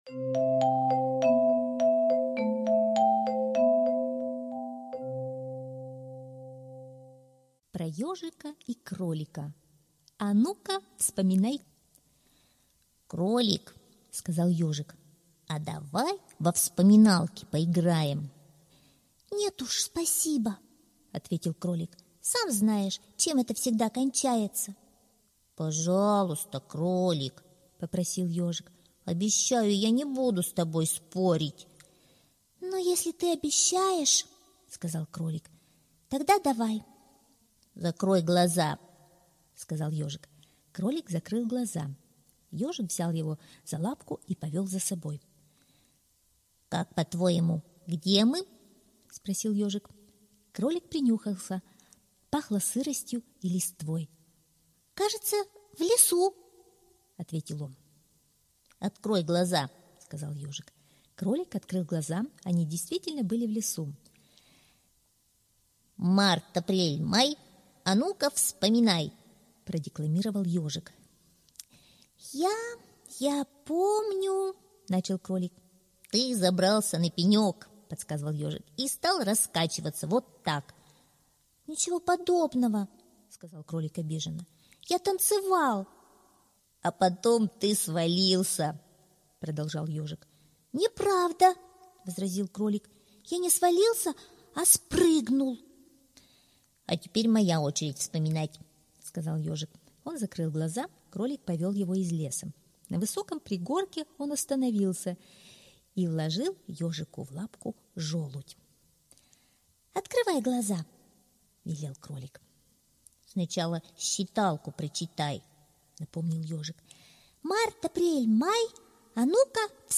Аудиосказка «Про Ёжика и Кролика: А ну-ка, вспоминай!»